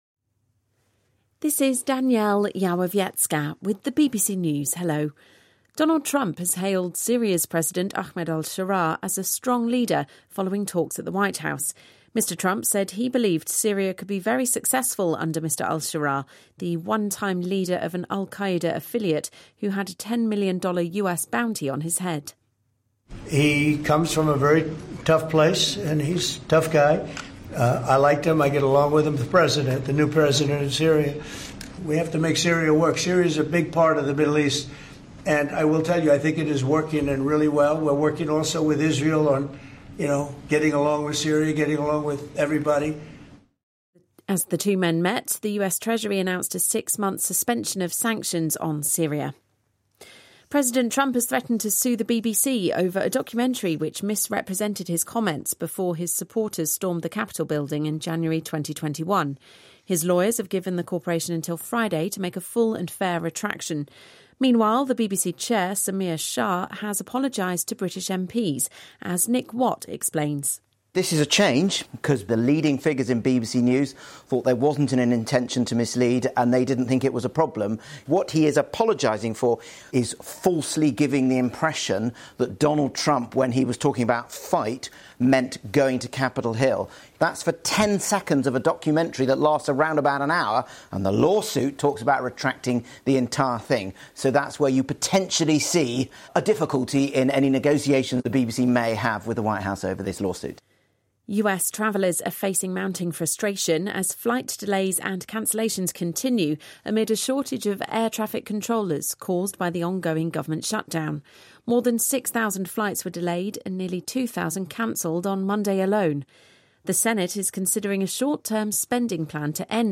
BBC新闻